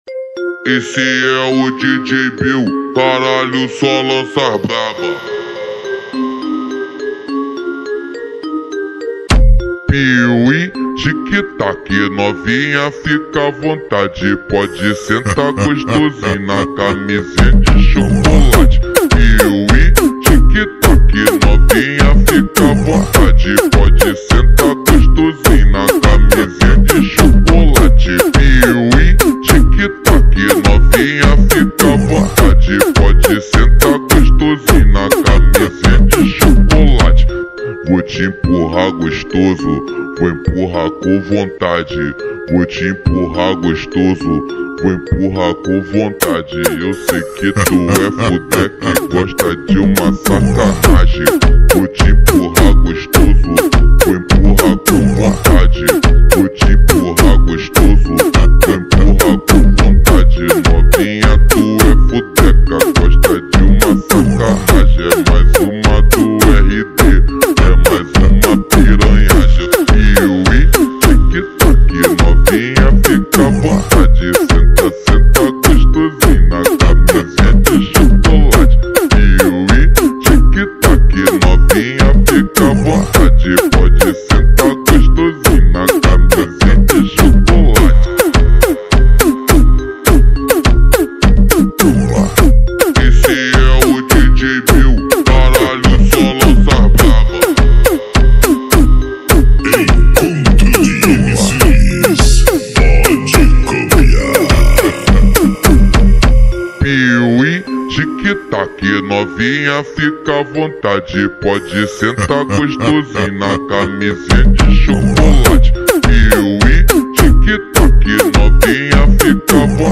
با ریتمی کند شده
فانک